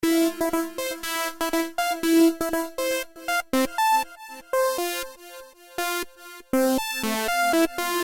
标签： 120 bpm Electronic Loops Synth Loops 1.35 MB wav Key : Unknown
声道立体声